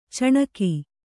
♪ caṇaki